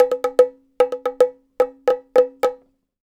Bongo Fill 04.wav